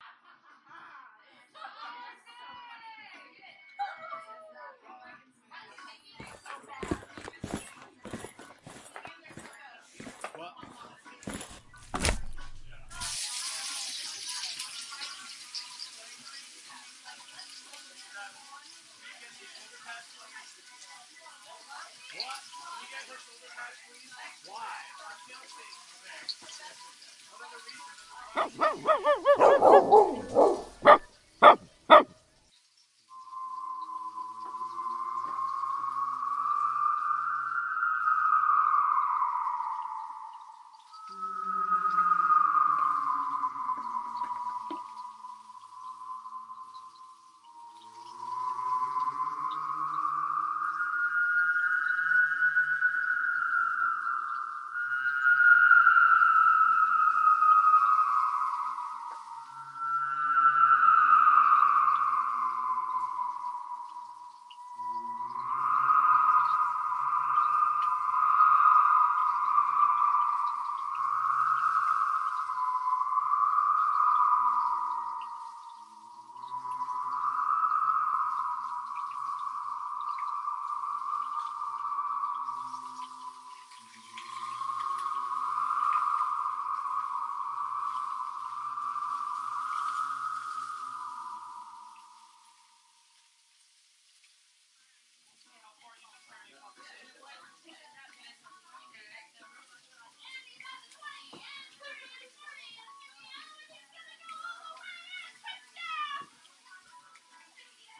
标签： 乱叫 飞船 烧烤 音乐 外星人 飞碟 科幻 烧烤
声道立体声